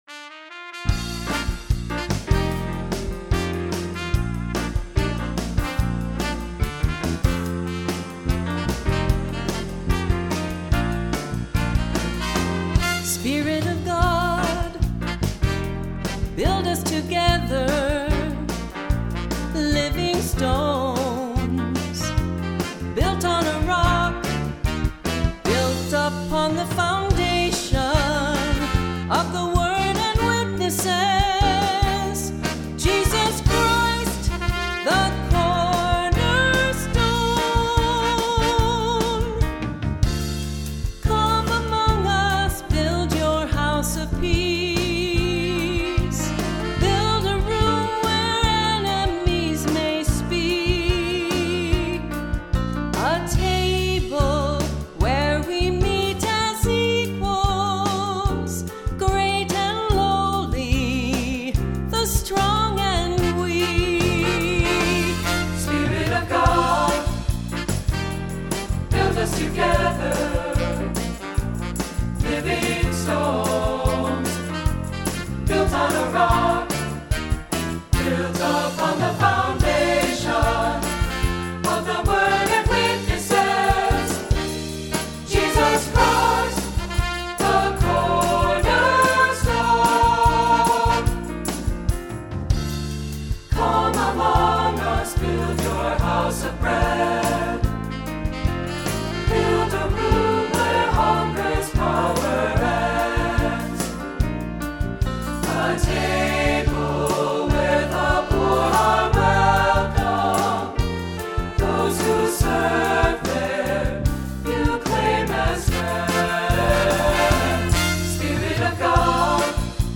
Accompaniment:      Keyboard, Flute
Music Category:      Christian